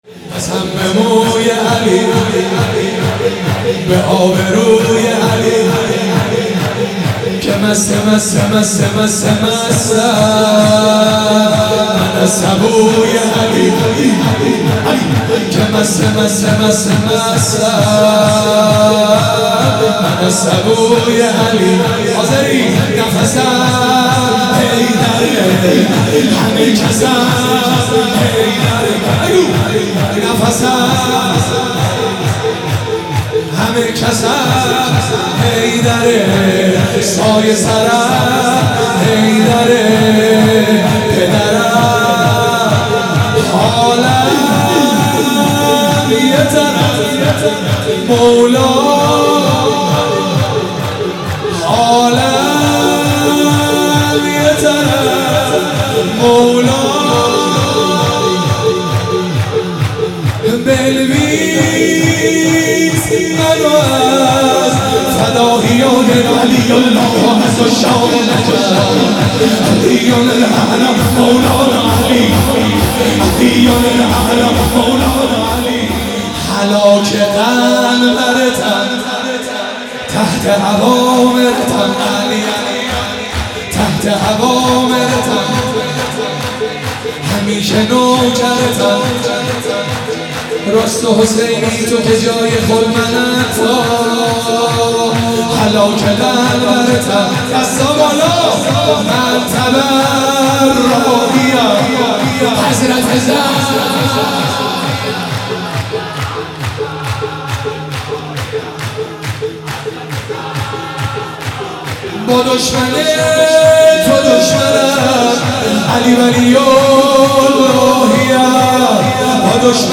مولودی امیرالمومنین علی علیه السلام